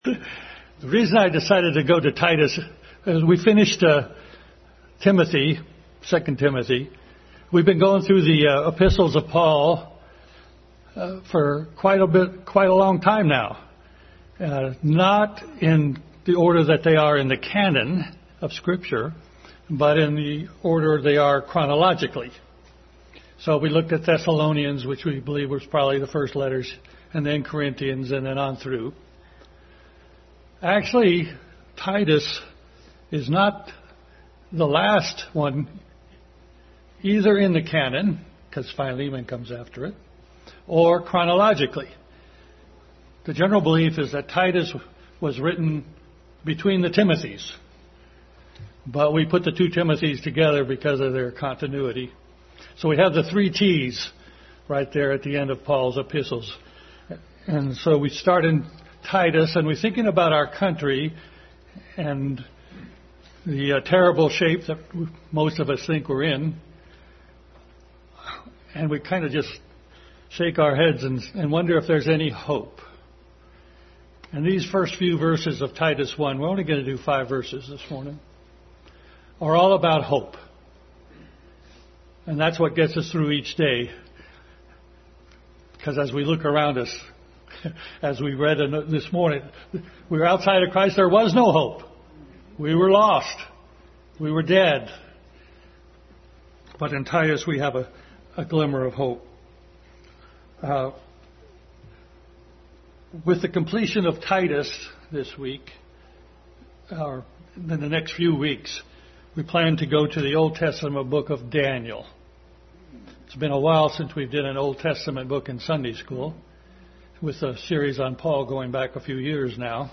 Titus 1:1-5 Passage: Titus 1:1-5, 2:13, 3:7, Romans 3:4, 2 Timothy 1:9 Service Type: Family Bible Hour